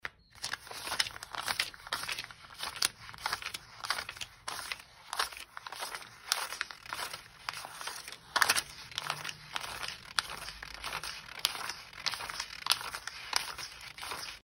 Звуки долларов